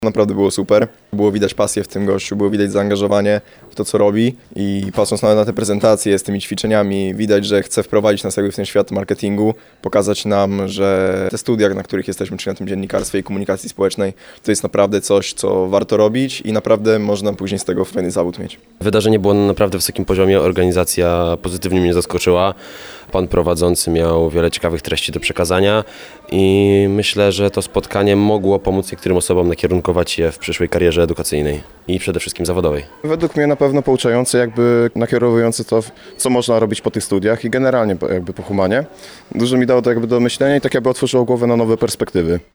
Po spotkaniu nasza reporterka rozmawiała także ze studentami. Jak ocenili kolejną odsłonę cyklu „Poznaj Swojego Pracodawcę”?